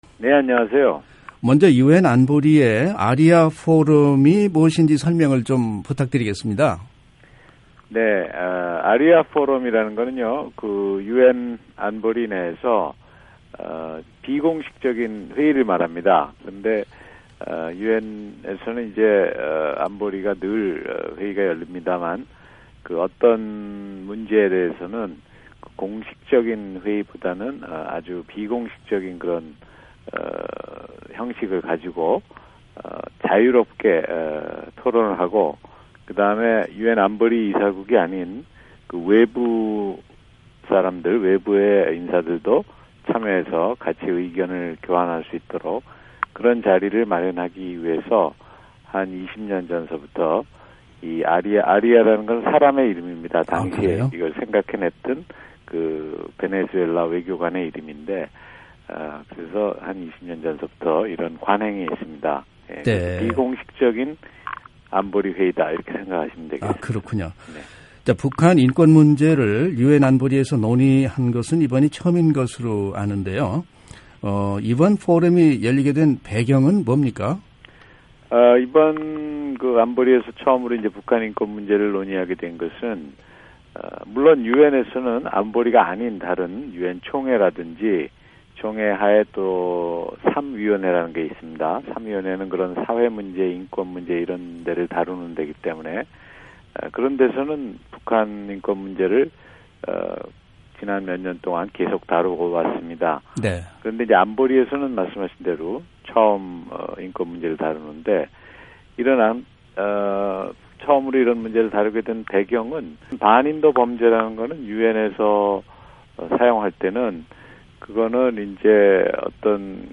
[인터뷰 오디오 듣기] 오준 유엔주재 한국대사